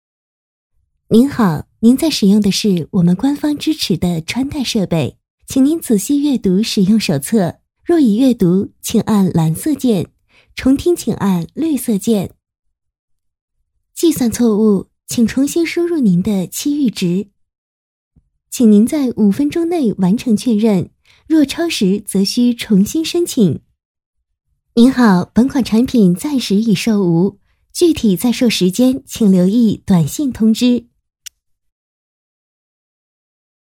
女220-提示音{年轻嗲气版}
女220专题广告 v220
女220-提示音-年轻嗲气版-.mp3